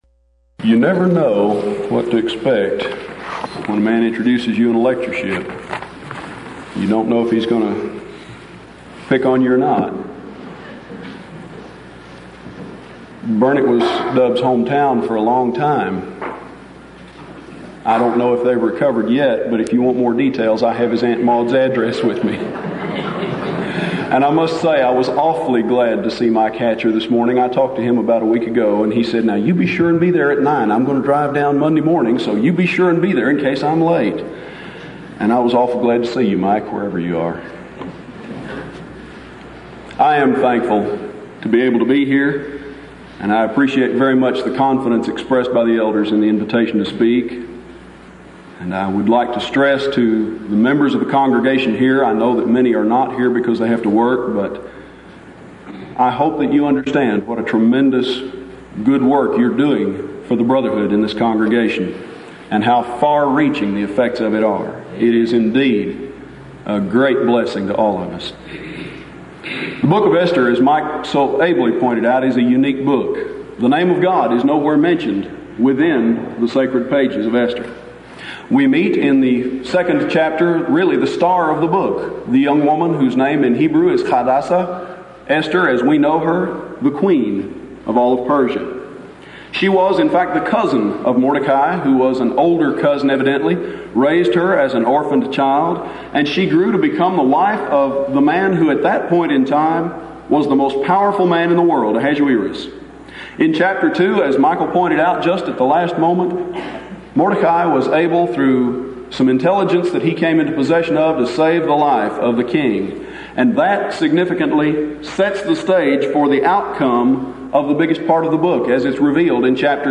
Event: 1992 Denton Lectures Theme/Title: Studies In Ezra, Nehemiah And Esther
lecture